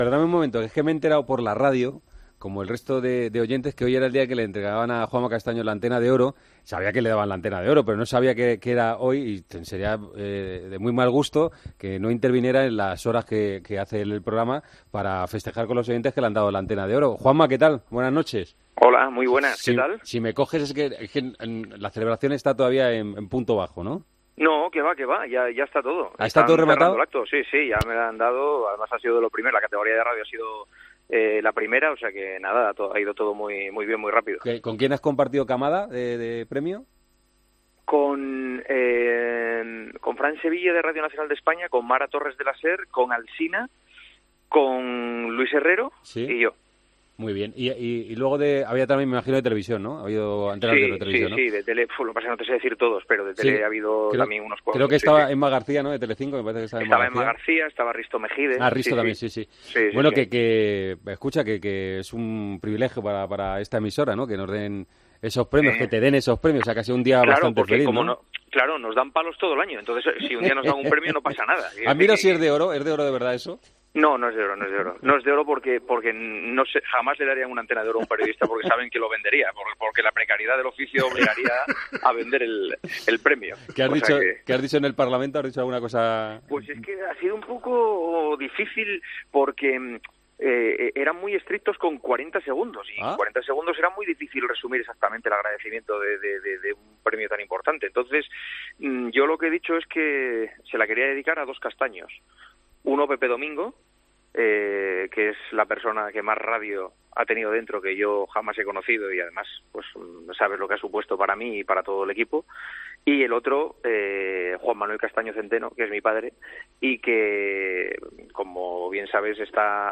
El presentador y director de El Partidazo de COPE ha recibido este sábado la Antena de Oro y se ha pasado por el Tramo final de Tiempo de Juego para festejarlo con los oyentes.
Una vez acabado el evento, se pasó por los micrófonos de Tiempo de Juego para festejar con los oyentes este galardón y ha desvelado en qué consistió su discurso de agradecimiento.